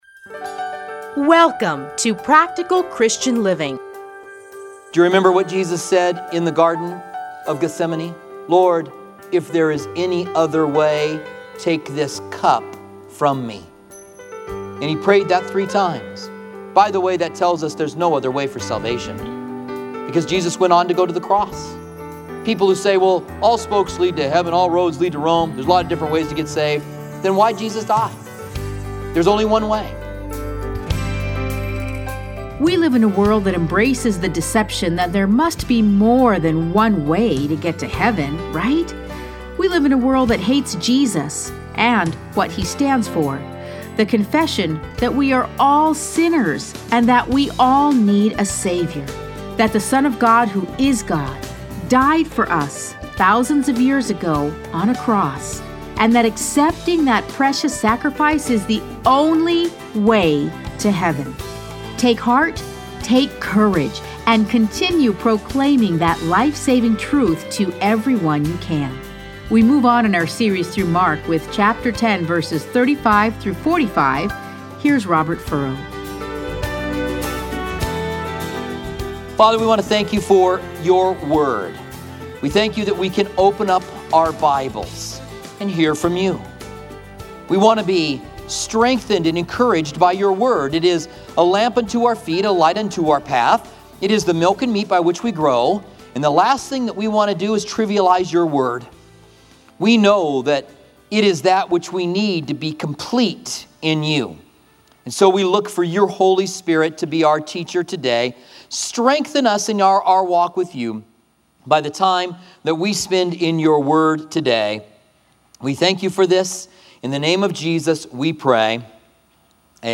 Listen to a teaching from Mark 10:35-45.